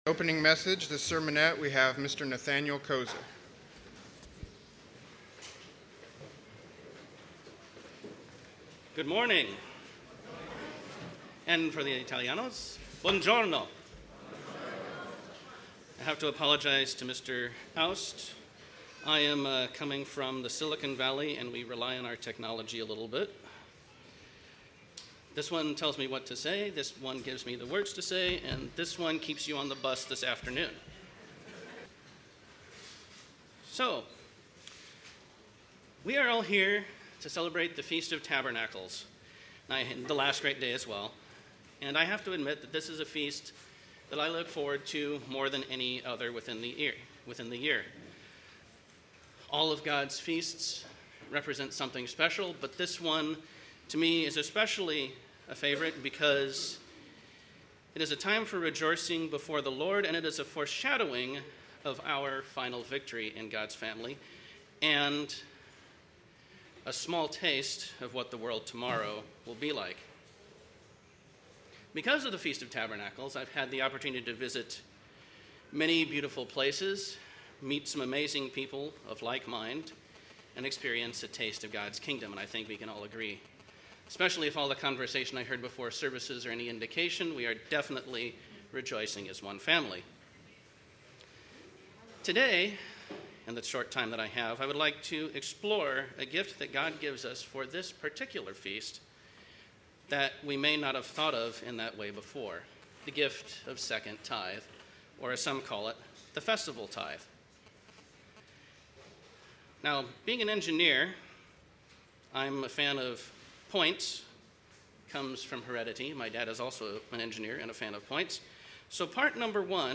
Sermonette